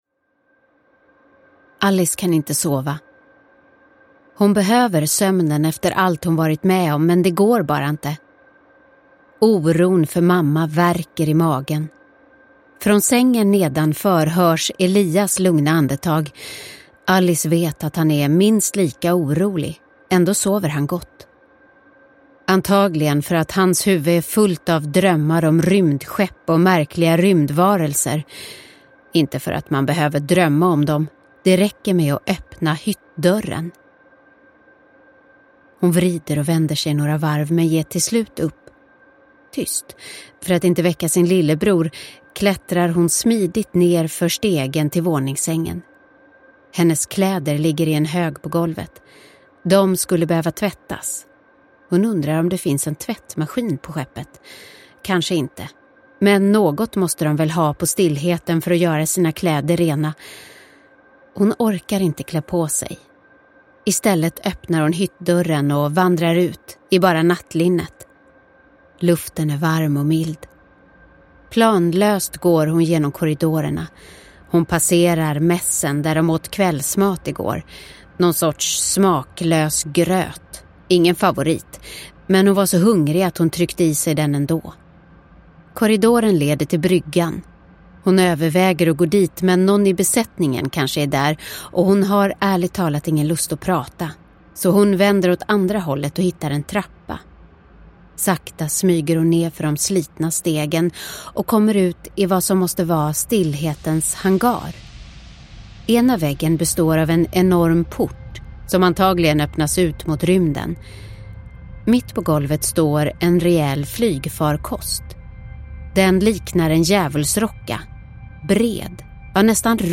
Järnrosen – Ljudbok – Laddas ner